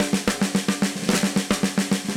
AM_MiliSnareB_110-03.wav